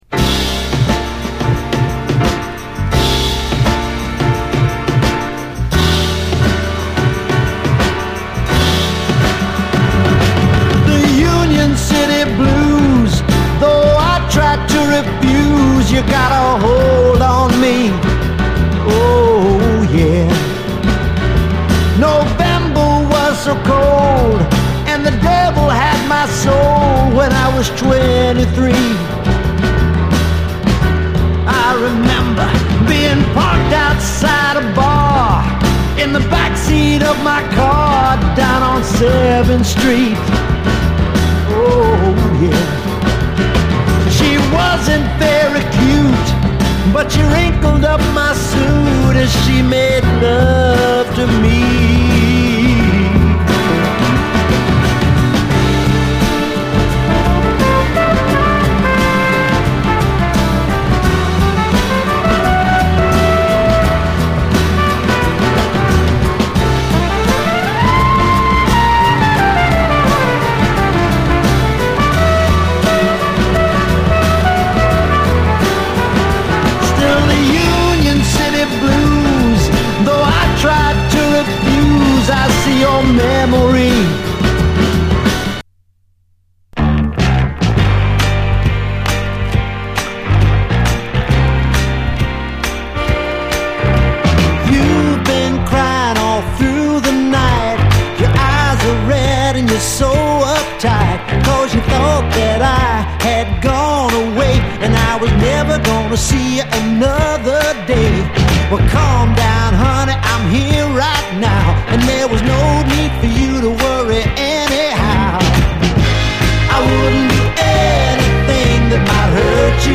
ソウル・クラシックの好カヴァー満載で全編最高なラヴァーズ名盤